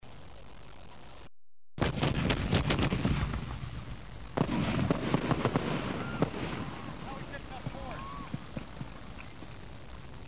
جلوه های صوتی
دانلود صدای بمب و موشک 30 از ساعد نیوز با لینک مستقیم و کیفیت بالا